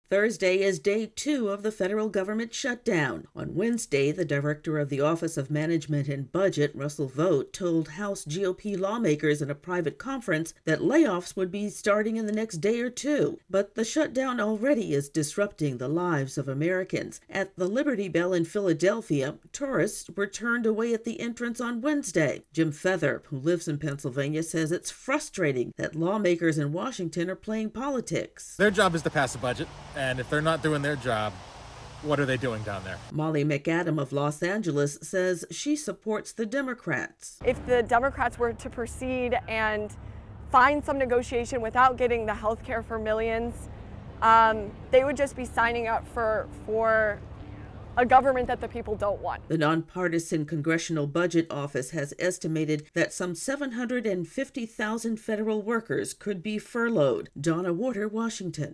reports on Day 2 of a federal government shutdown.